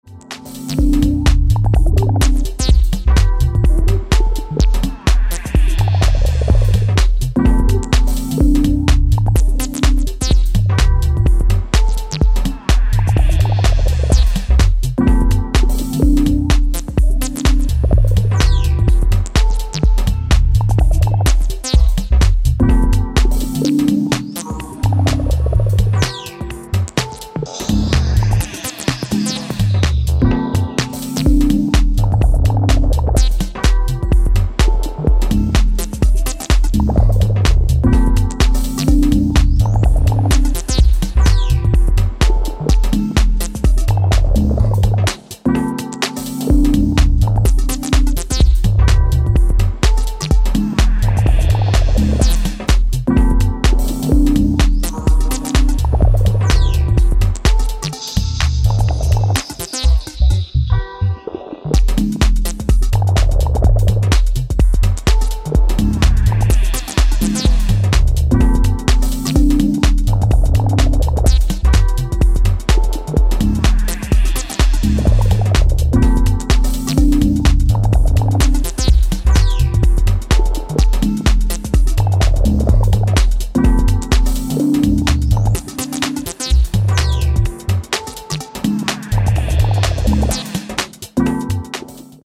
The Imprint Prepared A 10" Split-ep Teaser